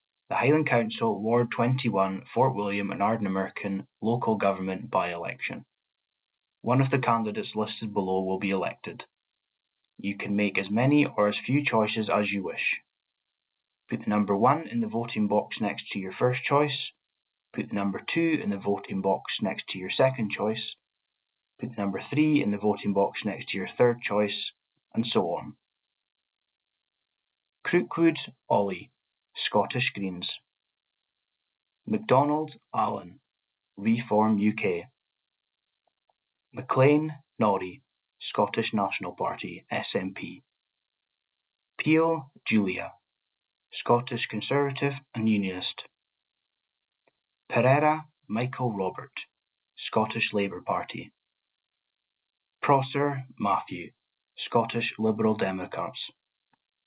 Spoken Ballot for Ward 21 - Fort William and Ardnamurchan